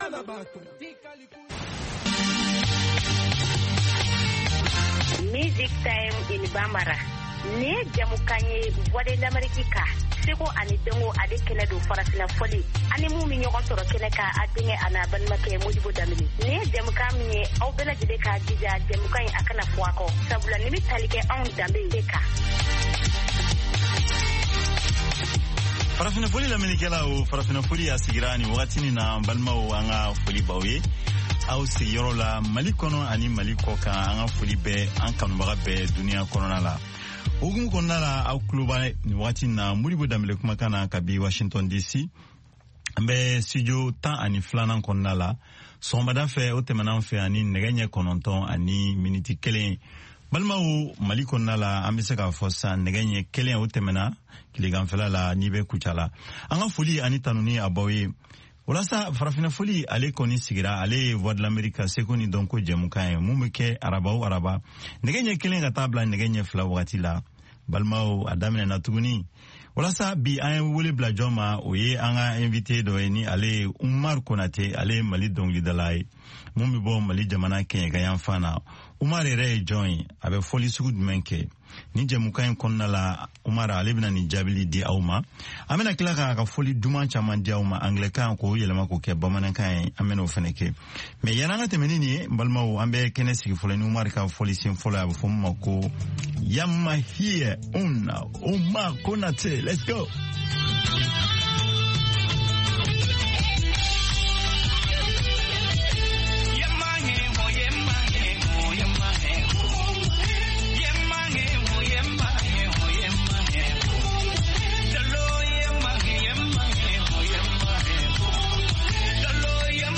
Bulletin d’information de 17 heures
Bienvenu dans ce bulletin d’information de VOA Afrique.